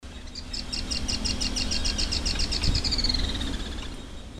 Curutié Ocráceo (Limnoctites sulphuriferus)
Nombre en inglés: Sulphur-bearded Reedhaunter
Fase de la vida: Adulto
Localidad o área protegida: Delta del Paraná
Condición: Silvestre
Certeza: Observada, Vocalización Grabada